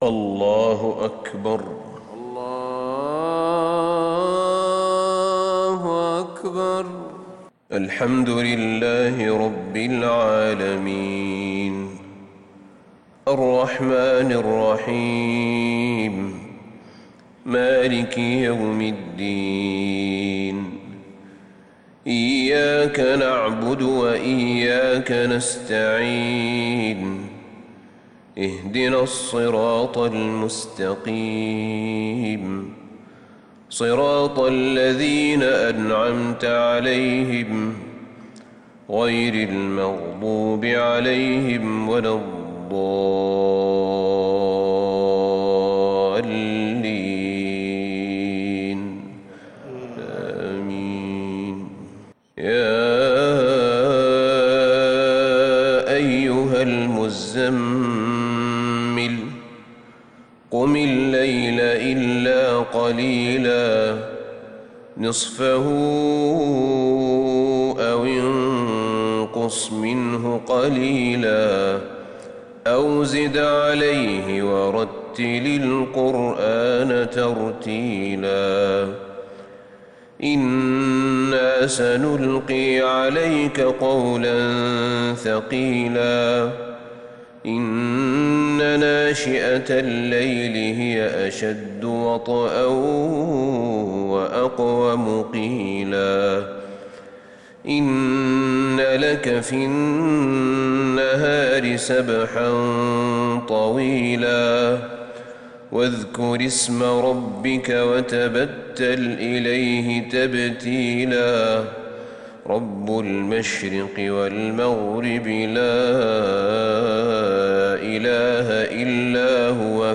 صلاة الفجر للشيخ أحمد بن طالب حميد 8 ربيع الأول 1442 هـ
تِلَاوَات الْحَرَمَيْن .